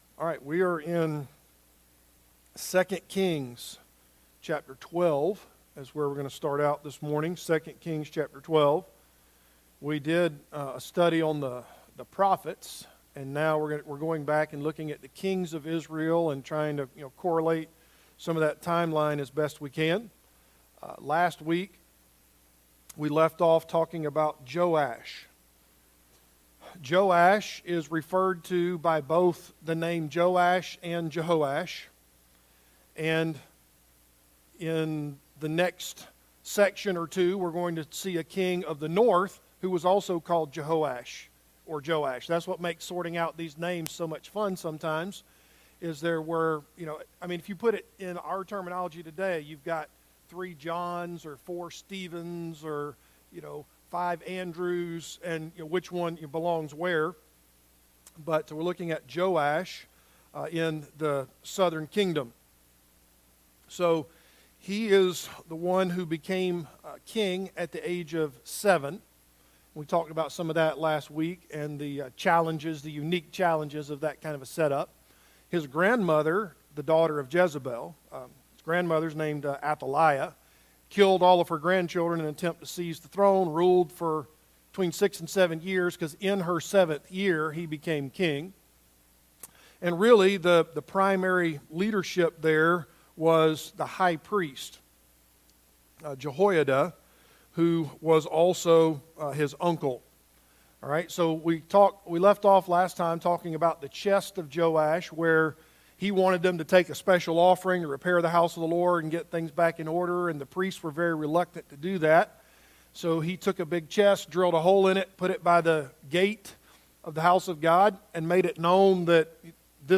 Passage: 2 Kings 12 Service Type: Adult Sunday School Class « Isaac